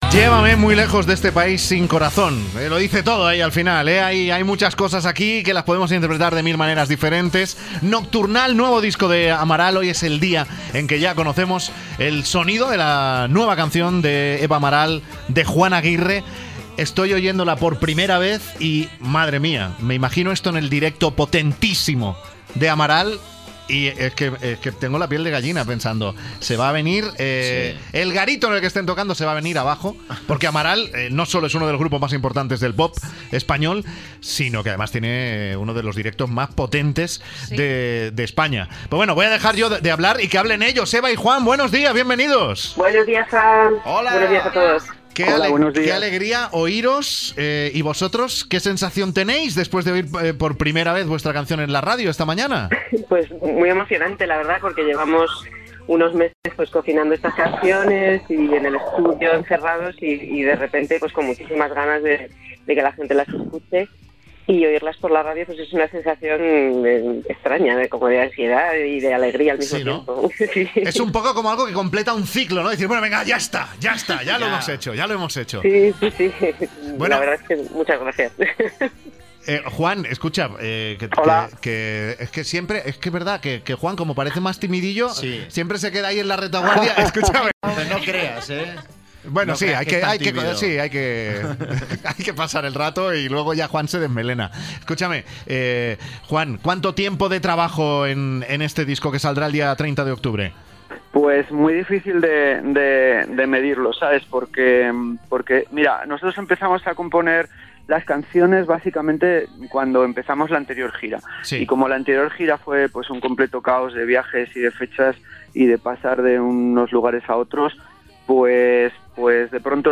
Frank Blanco habla con los componentes del grupo Amaral que le presentan su nuevo tema "Llévame muy lejos".